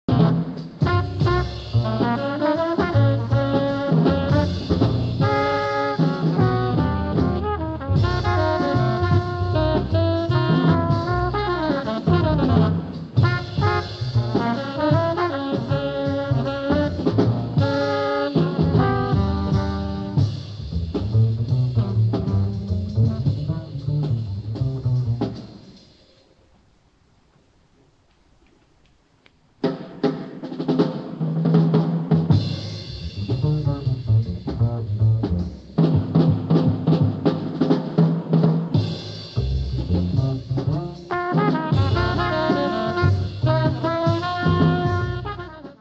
STUDIO RECORDING SESSIONS OF NEW YORK CITY 03/04/1958